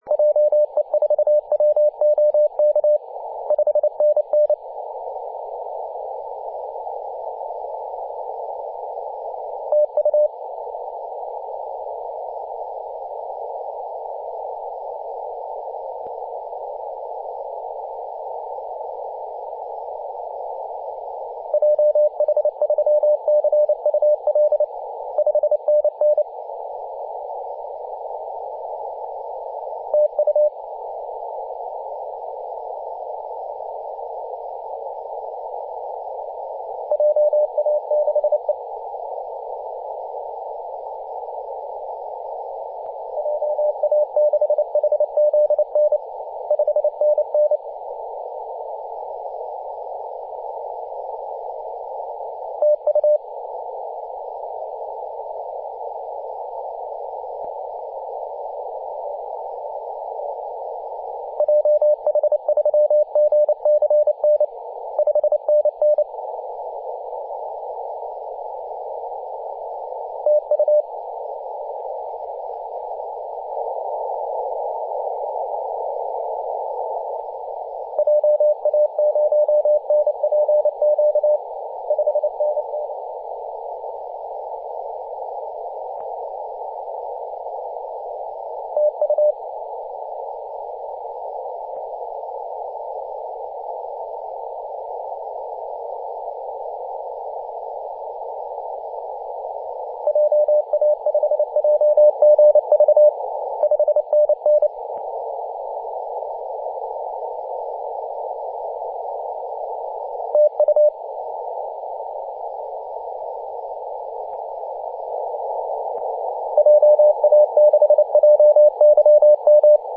21.040MHz CW